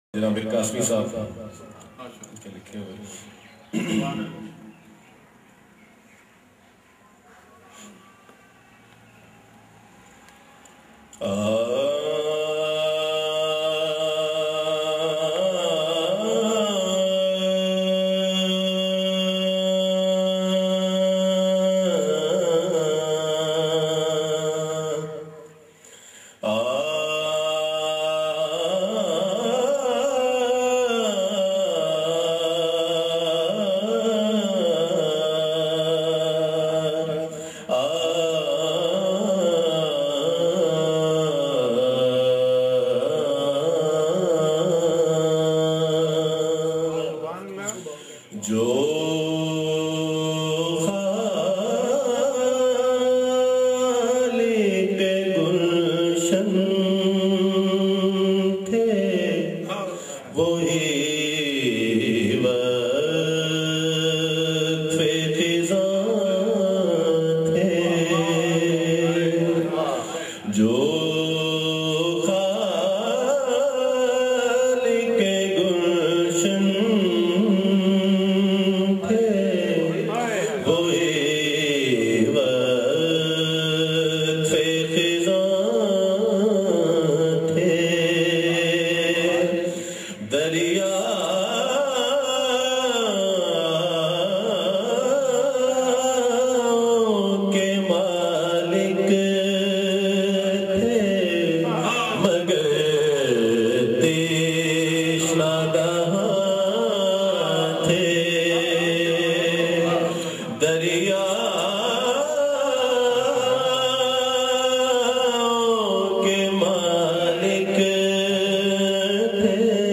in best audio quality.